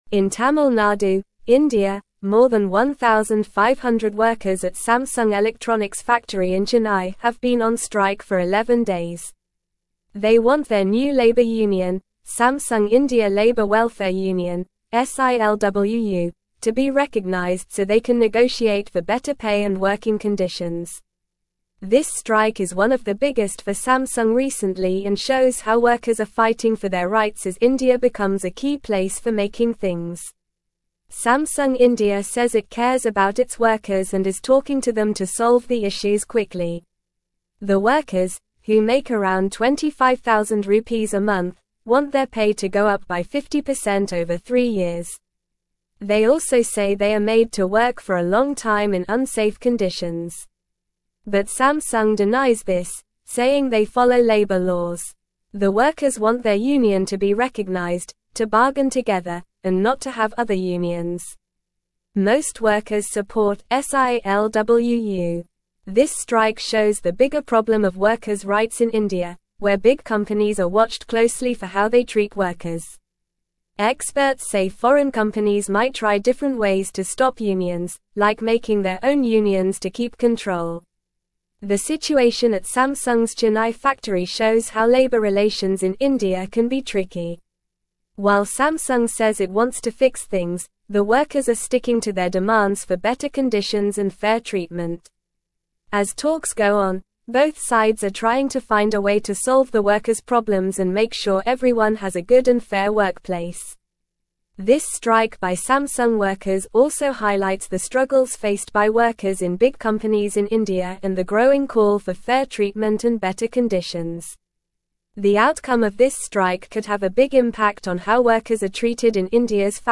Normal
English-Newsroom-Upper-Intermediate-NORMAL-Reading-Samsung-Workers-in-India-Strike-for-Union-Recognition.mp3